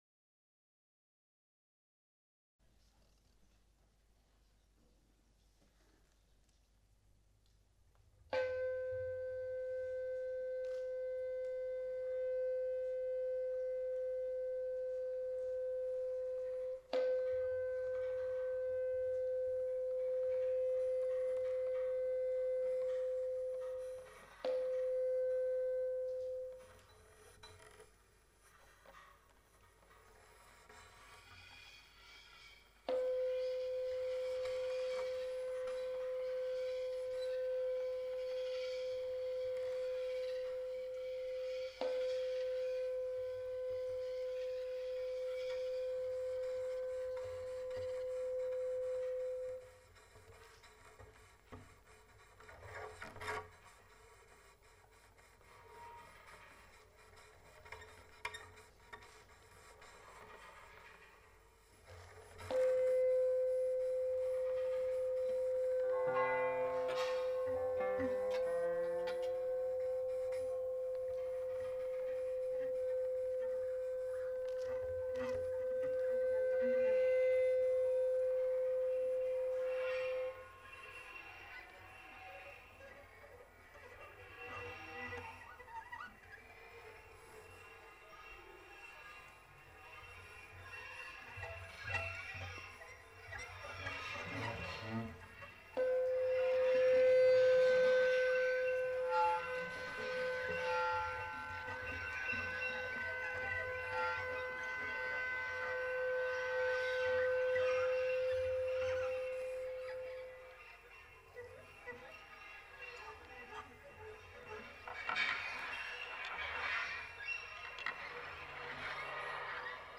L'intégralité de ce concertino en palliers a été enregistré et remixé par mes soins (autant dire : mal) pour vous le rendre audible ici .
trio.mp3